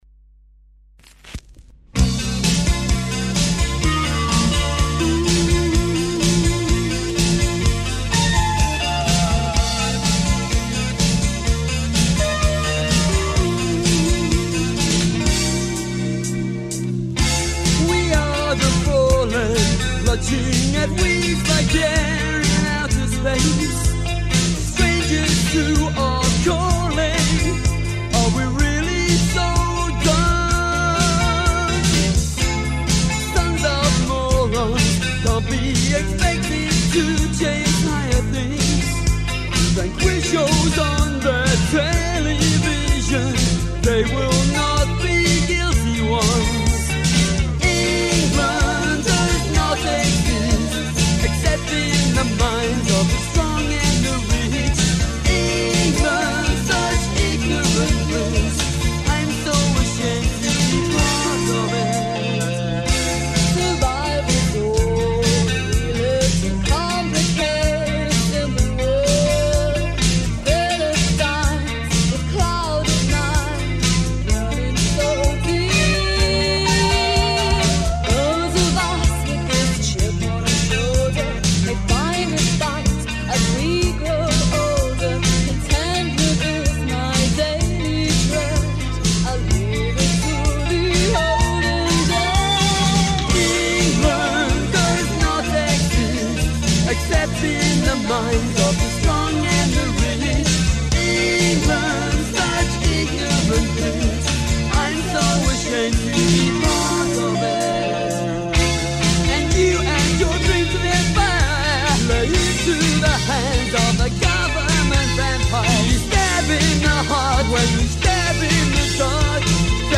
there was an 80s band